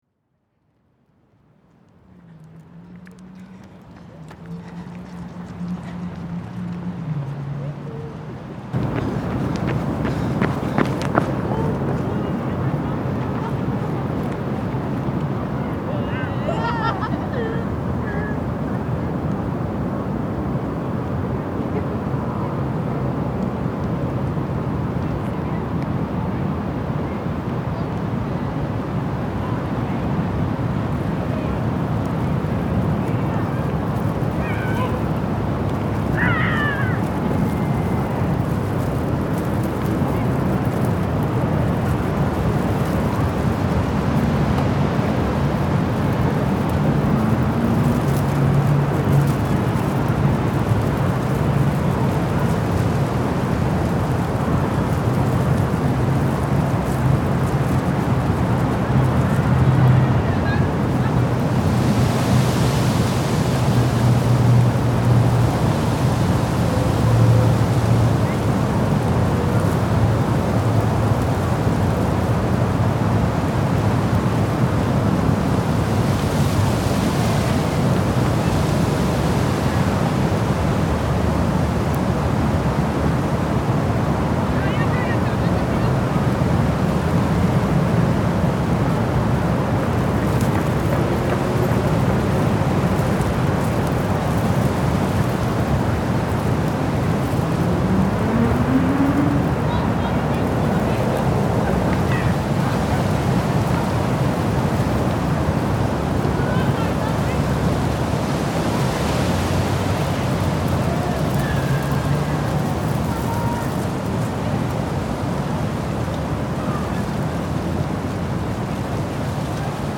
Hyde Park
8 locations in london, 4 urban spaces, 4 unspoilt nature…
8LondonLocations_03_HydePark.mp3